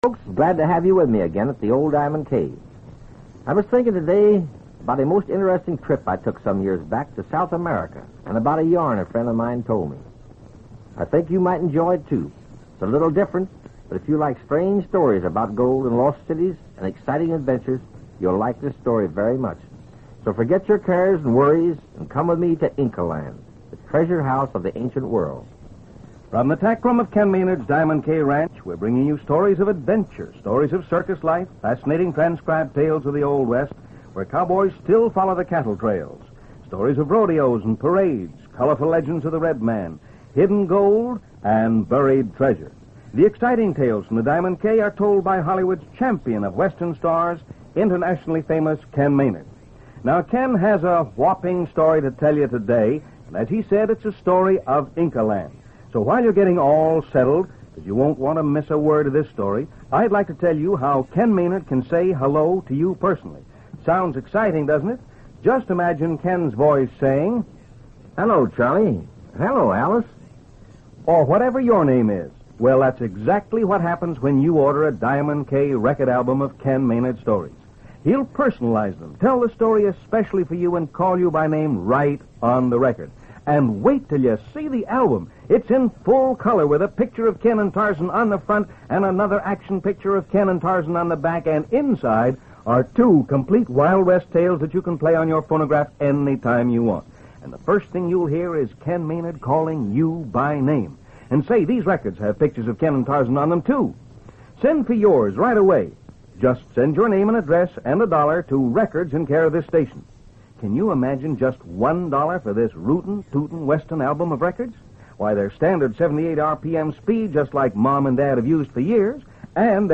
"Tales from the Diamond K" was a syndicated radio show aimed at a juvenile audience, broadcasted during the mid-1950s. - The show featured a variety of stories, mostly set in the Old West, and was designed to entertain and educate its young listeners. - Ken Maynard, a pioneer singing cowboy and film star, hosted the show, introducing a different story each day.